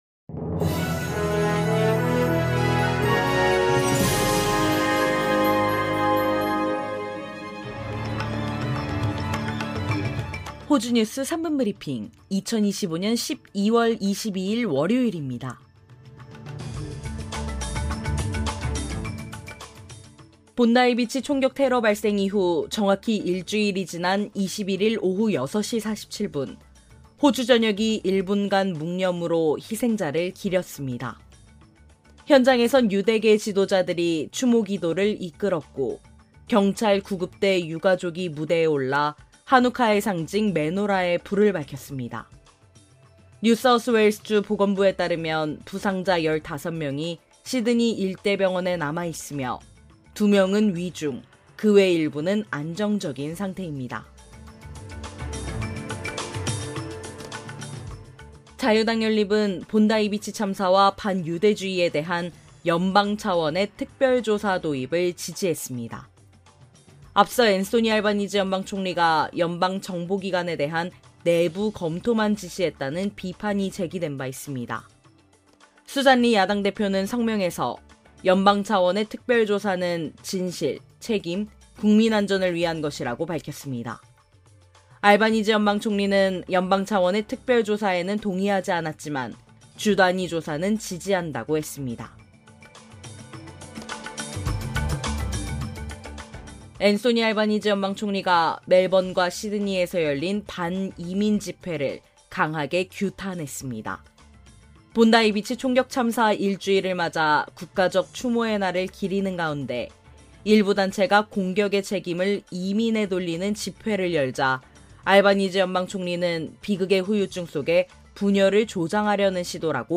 호주 뉴스 3분 브리핑: 2025년 12월 22일 월요일